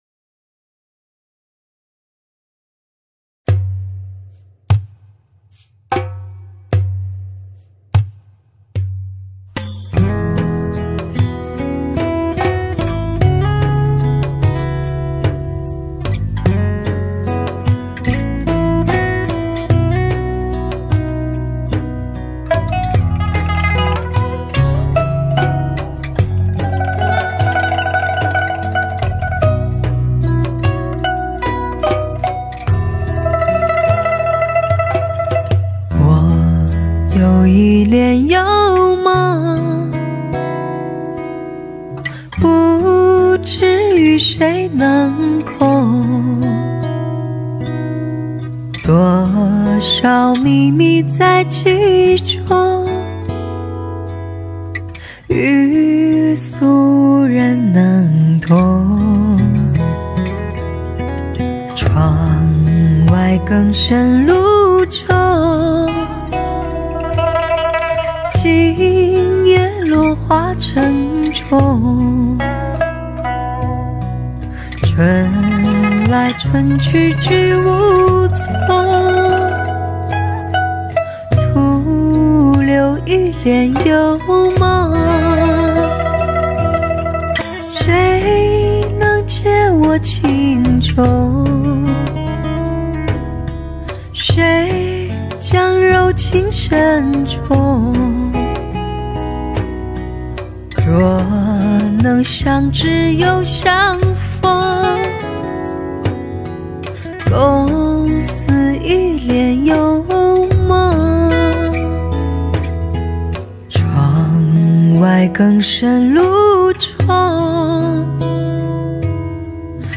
Popular Chinese Song
Solo Recorder